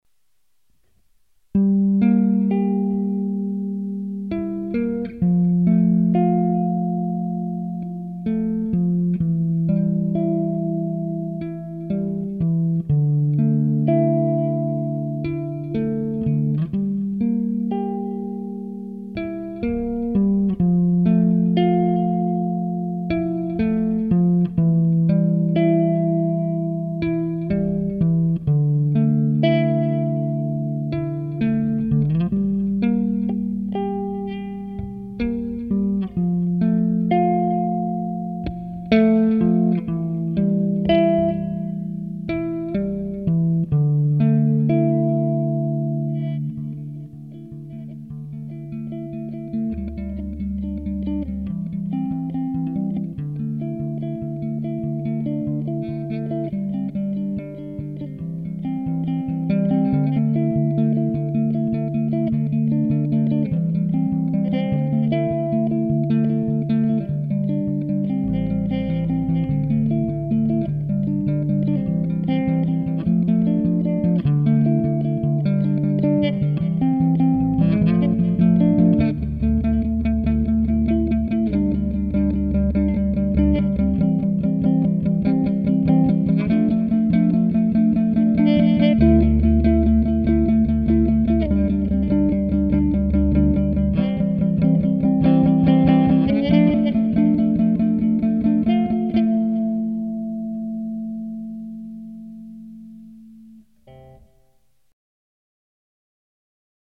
bass solo